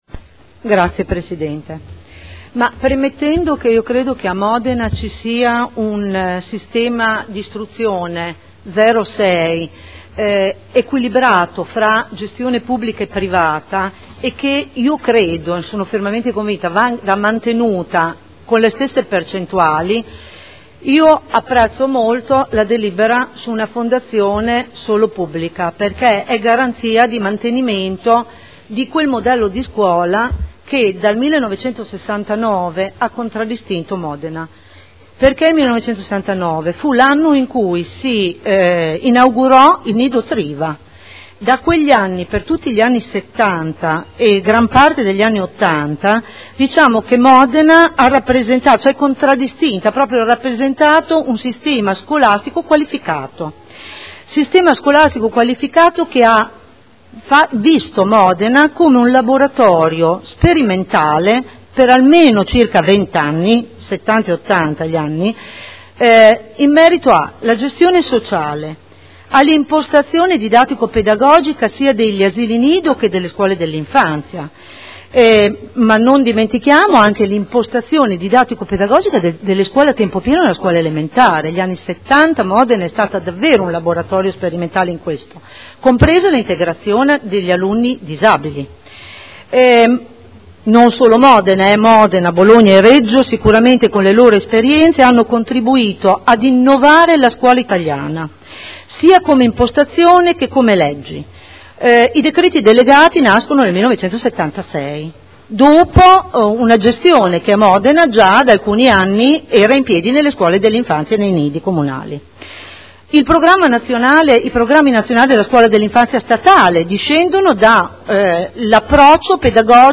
Cinzia Cornia — Sito Audio Consiglio Comunale
Seduta del 03/05/2012. Dibattito su proposta di deliberazione, emendamenti e Ordine del Giorno sulle scuole d'infanzia comunali